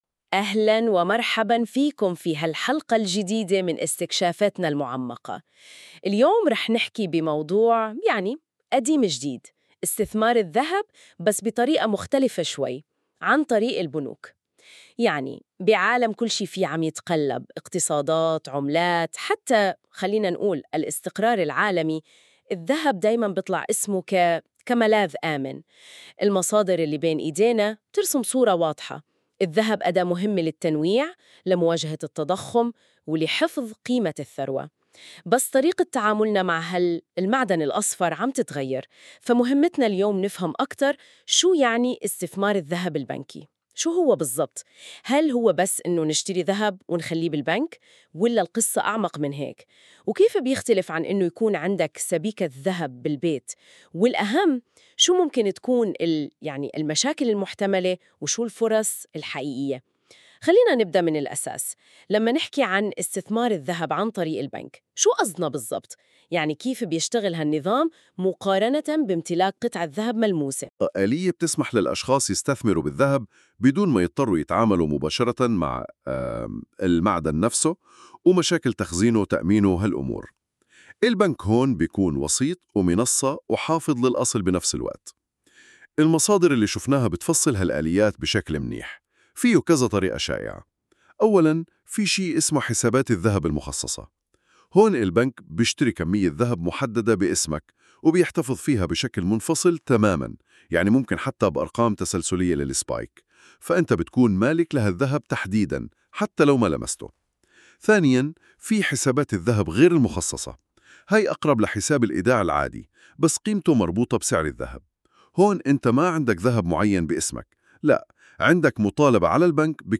يمكنك الاستماع إلى هذا المقال بدلاً من قراءته عبر المقطع الصوتي التالي👇